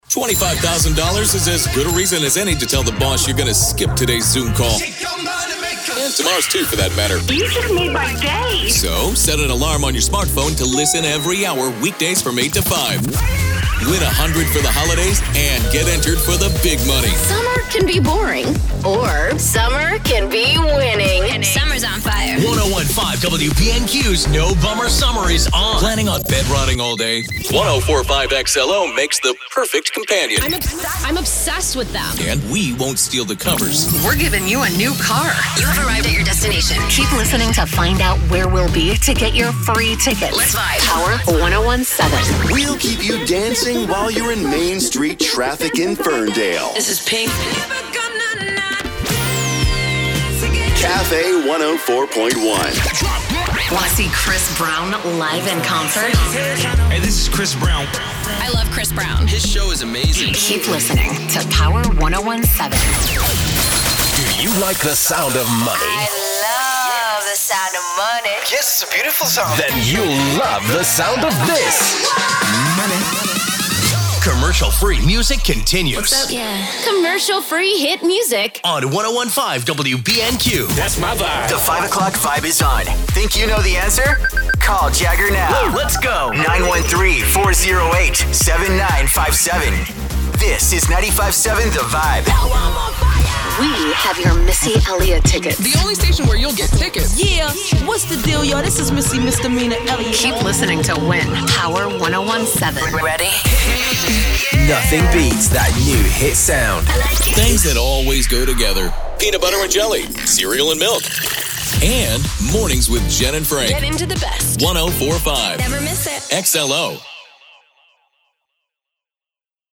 Here is a mix of promos and sweepers from their affiliate stations that I’ve worked on.
Over the last 20 years of producing imaging my style has become a lot less cluttered with voice and production effects because the listener is always top of mind.